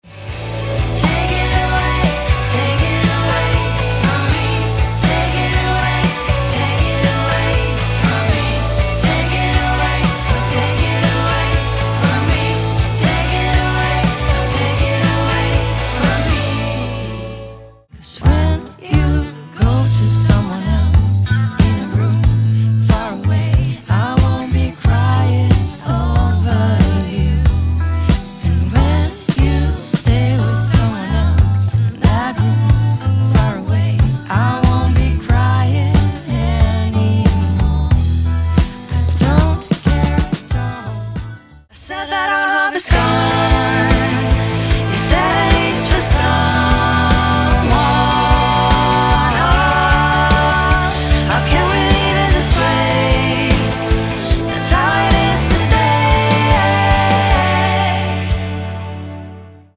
• Pop
• Rock